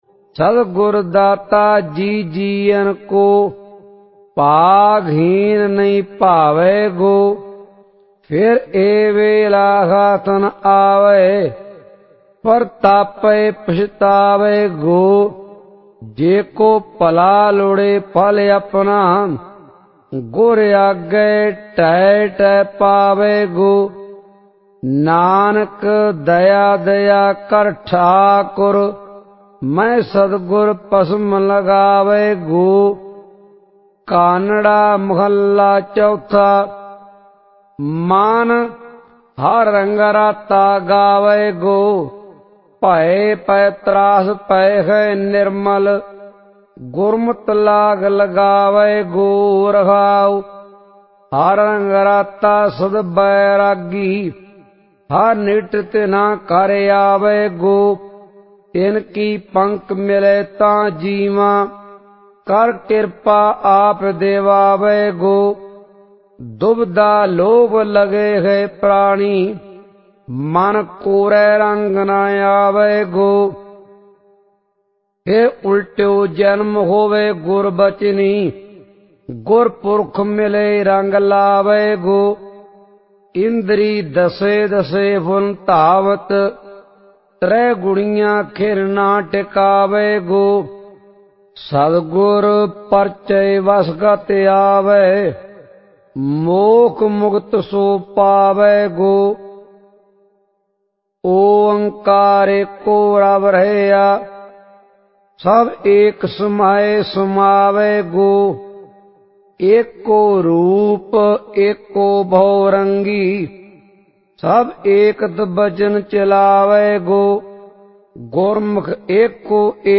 SGGS Sehaj Paath